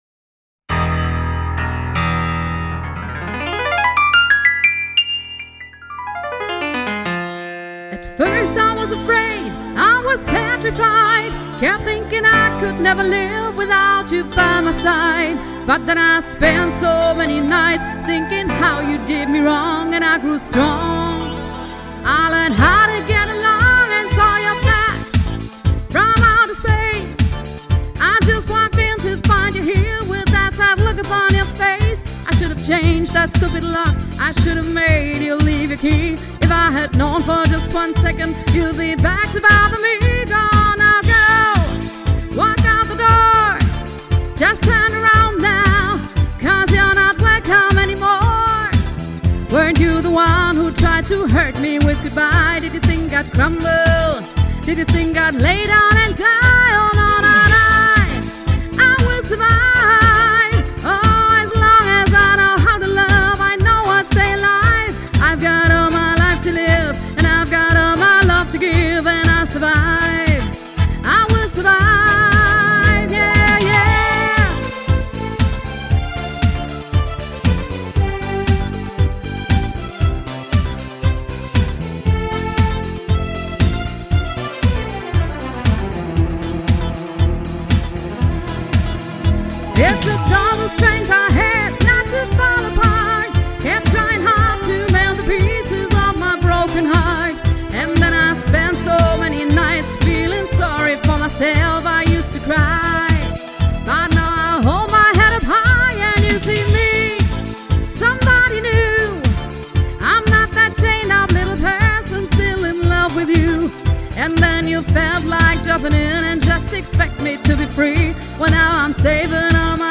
Tanzband aus Monzingen
• Coverband